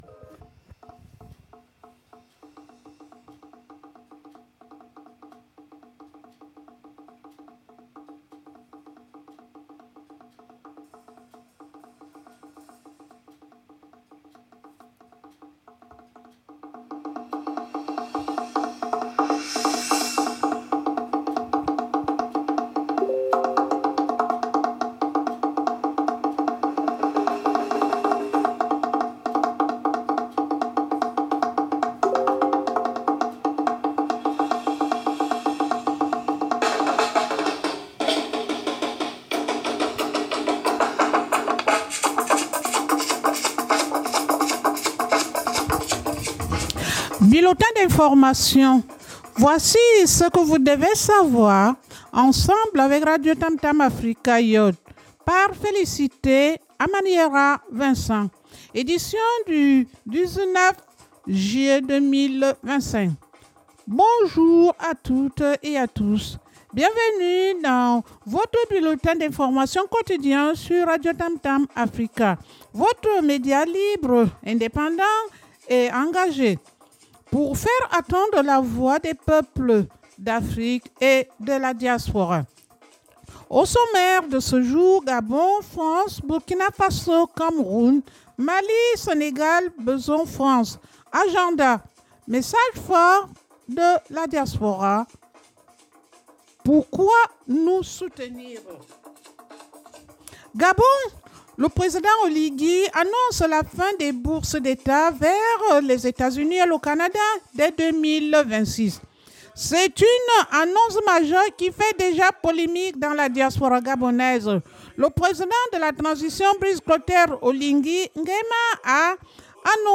Bienvenue dans votre bulletin d’information quotidien sur RADIOTAMTAM AFRICA,